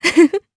Seria-Vox_Happy2_jp.wav